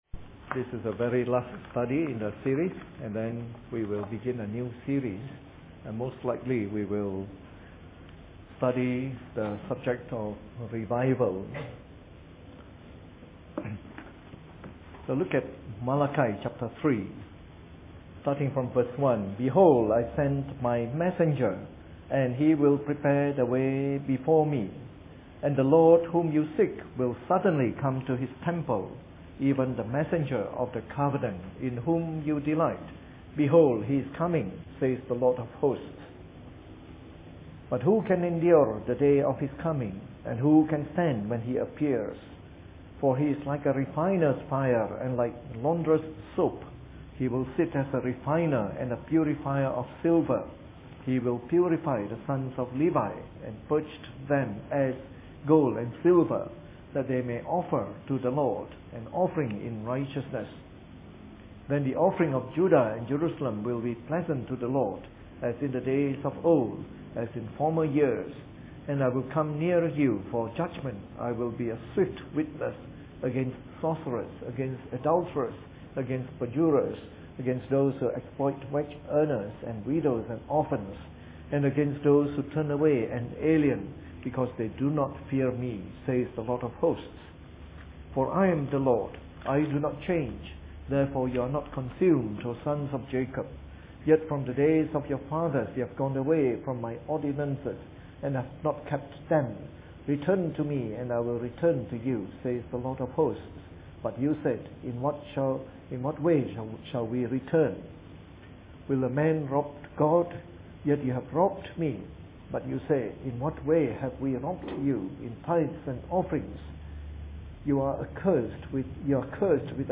Preached on the 20th of February 2013 during the Bible Study, the last in our series of talks on “The Minor Prophets.”